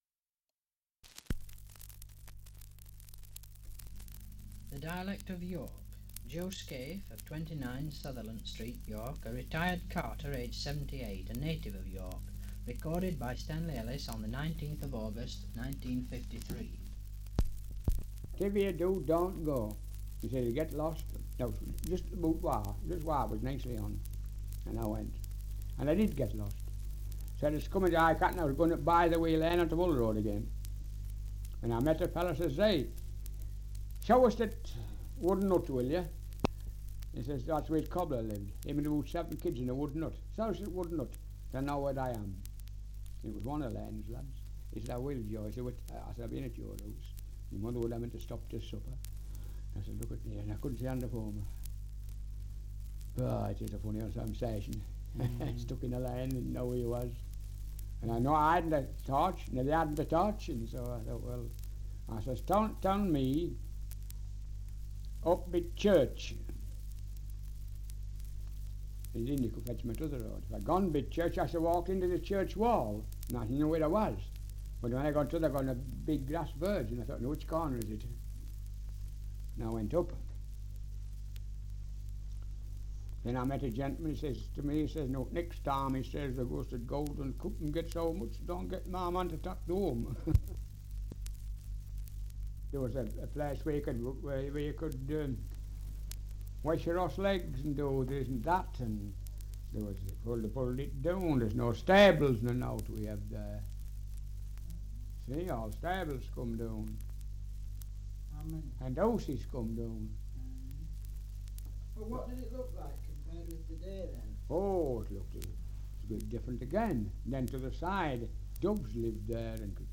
Survey of English Dialects recording in York, Yorkshire
78 r.p.m., cellulose nitrate on aluminium